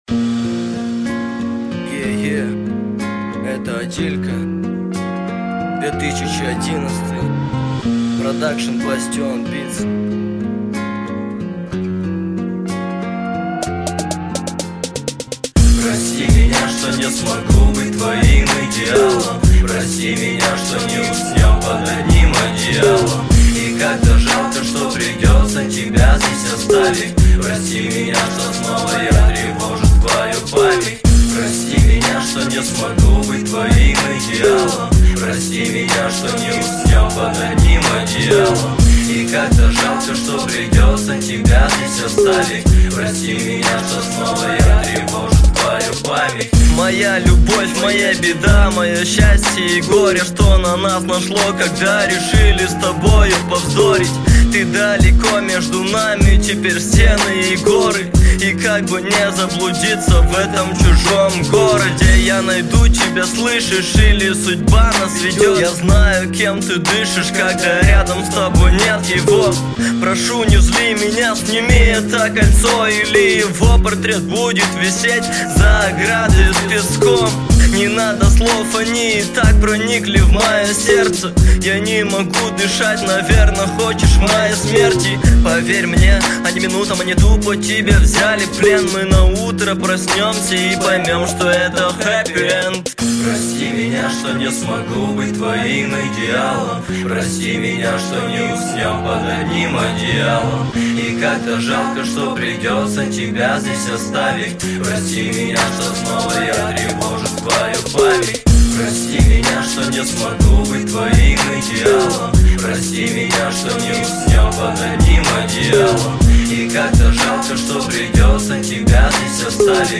Главная » Русский реп, хип-хоп